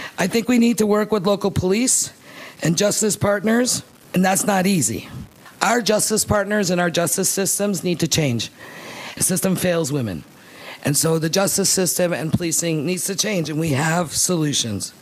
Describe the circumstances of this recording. spoke at the press conference